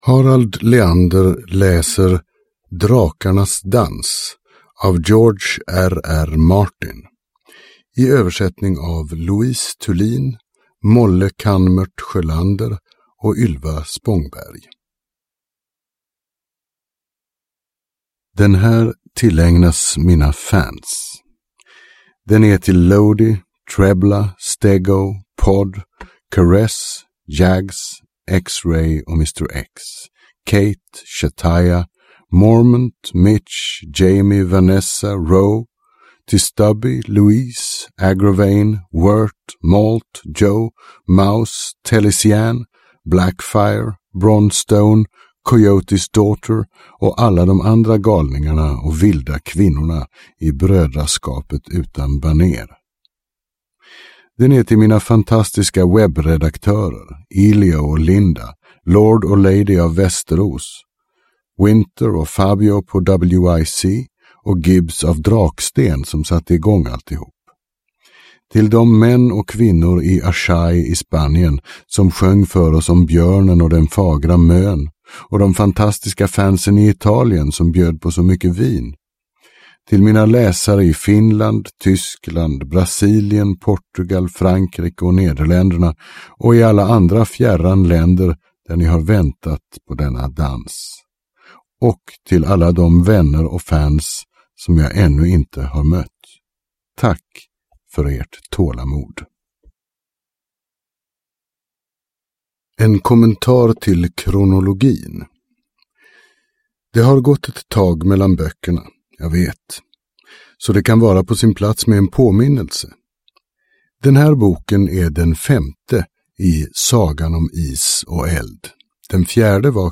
Game of thrones - Drakarnas dans – Ljudbok – Laddas ner